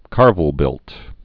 (kärvəl-bĭlt, -vĕl-)